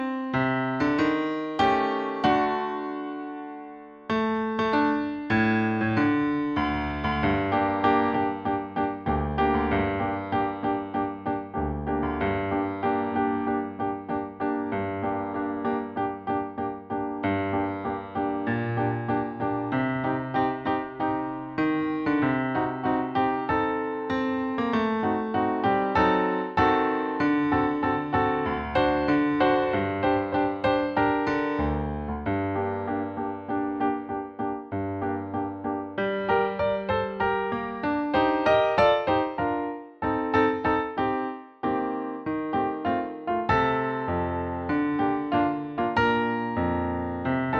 Produkt zawiera nagranie akompaniamentu pianina
I część: 96 bmp – wersja ćwiczeniowa
Nagranie nie zawiera rubat ani zwolnień.
Nagranie nie zawiera zwolnienie tempa w części Meno Mosso.
Nagranie dokonane na pianinie cyfrowym, strój 440Hz